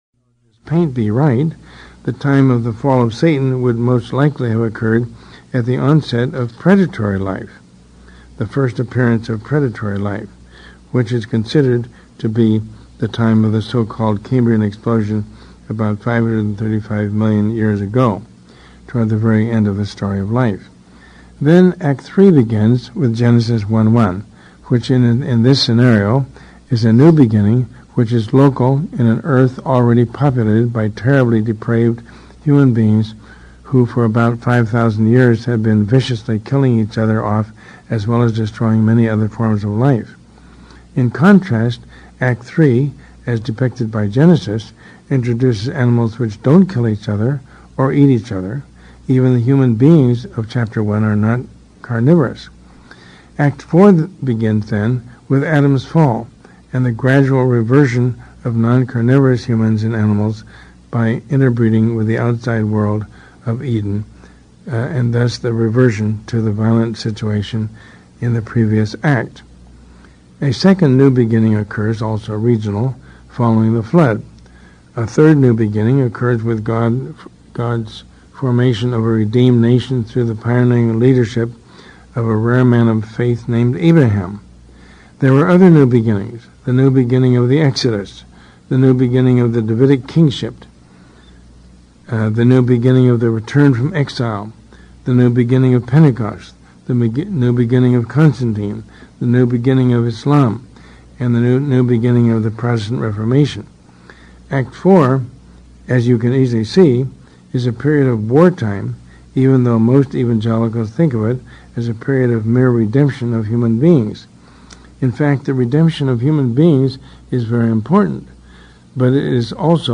First part of this audio lecture is missing. The audio begins from the middle of the transcript.
lesson19-lecture.mp3